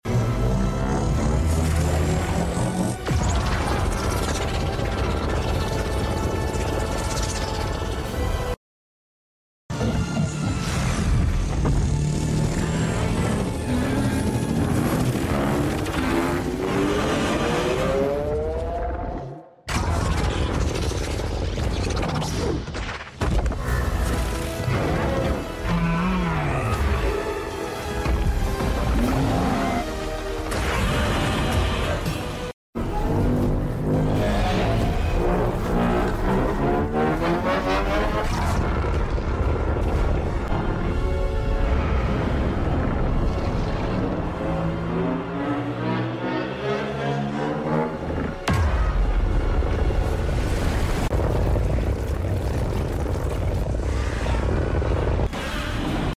I love the sound Godzilla makes when he’s powering up for Atomic Breath